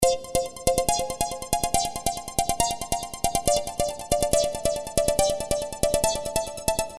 Tag: 140 bpm Techno Loops Synth Loops 1.18 MB wav Key : Unknown